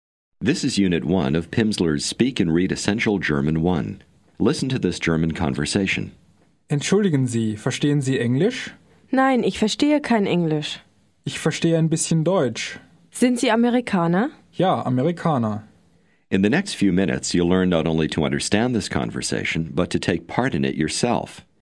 Аудио курс для самостоятельного изучения немецкого языка.